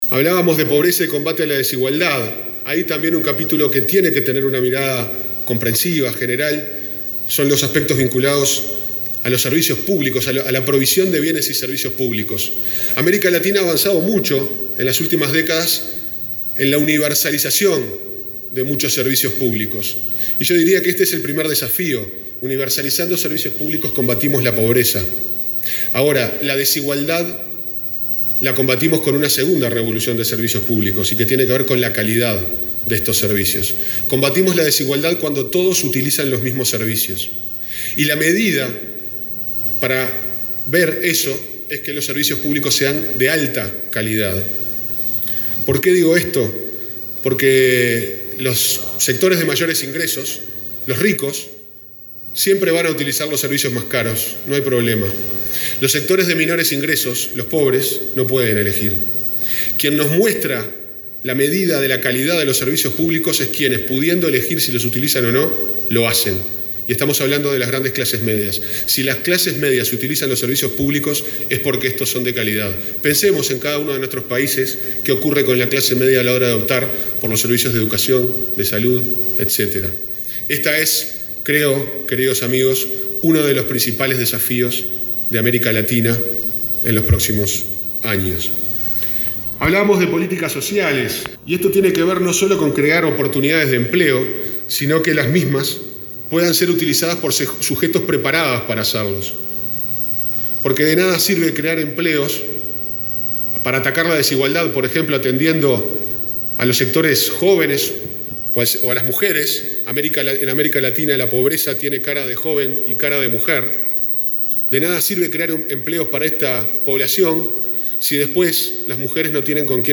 “Al universalizar los servicios públicos se combate la pobreza, pero a la desigualdad la atacamos mejorándolos”, afirmó el subsecretario de Economía, Pablo Ferreri, en la reunión de la Cepal. Destacó que la generación de empleo, formación y la creación de tiempo para las mujeres son medidas para atacar la desigualdad.